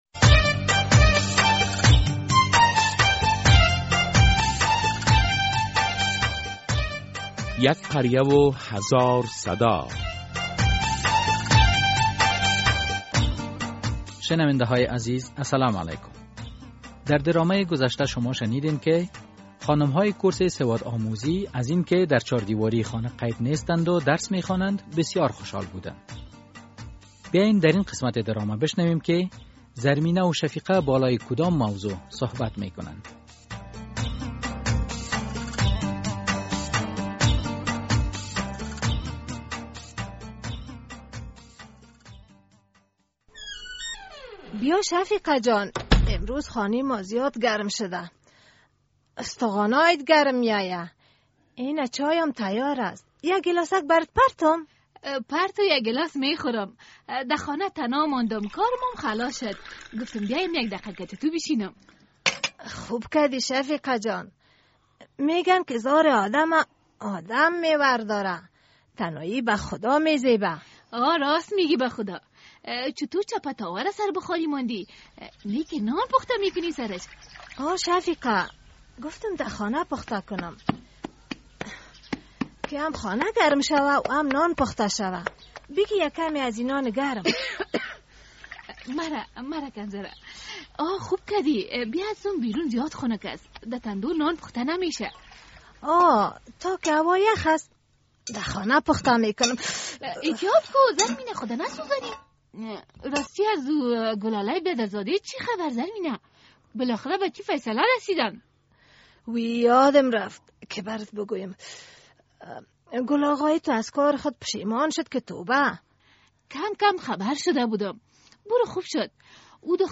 در ۱۸۸قسمت درامه یک قریه هزار صدا به مشکلات در بخش تعلیم پرداخته شده است.